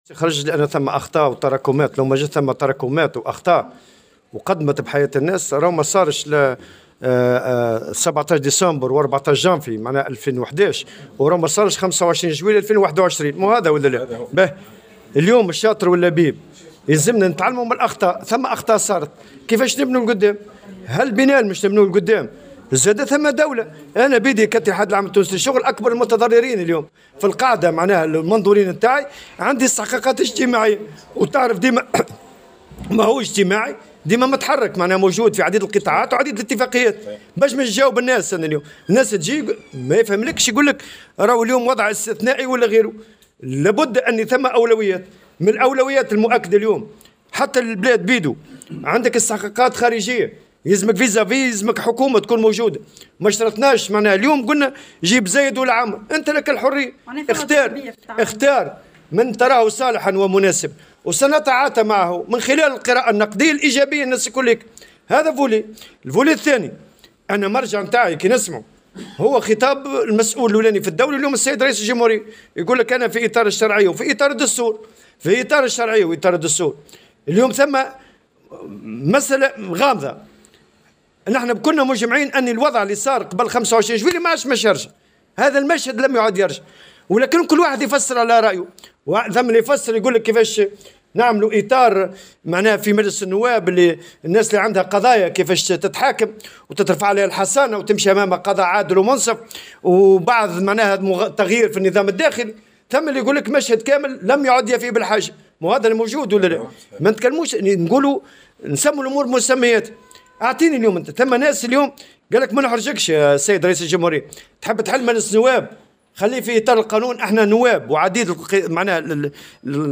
قال الأمين العام للاتحاد العام التونسي للشغل نور الدين الطبوبي، في تصريح للجوهرة أف أم، اليوم السبت، إن الاتحاد يعتبر أكبر المتضررين من قرارات 25 جويلية، بالنظر لتعطل النظر في الاستحقاقات الاجتماعية في ظل غياب حكومةٍ تتعامل معها المنظمة الشغيلة.
وأكد الطبوبي لدى إشرافه على إحياء يوم العلم، بالمنستير، أن الاستحقاقات الاجتماعية تشكل ضغطا على الاتحاد المطالب بالتفاعل مع قضايا الشغالين الذين لن يتفهموا مرور البلاد بوضع استثنائي، مشددا على أن الأولوية في المرحلة الحالية تتمثل في تشكيل حكومة تستجيب للاستحقاقات الداخلية والخارجية.